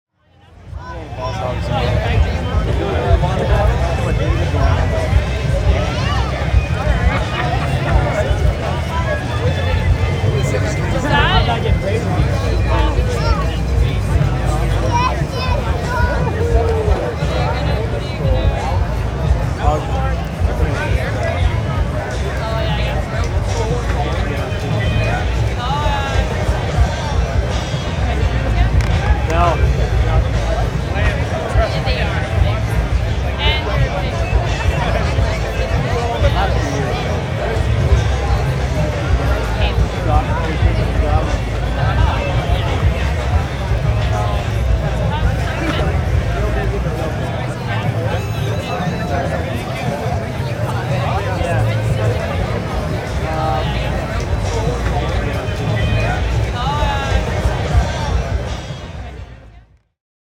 streetparty2.L.wav